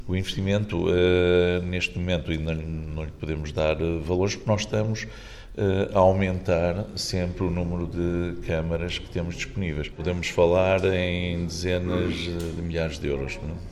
O sistema de vigilância é suportado financeiramente pelo município, que fica numa dezena de milhares de euros, como acrescentou Benjamim Rodrigues: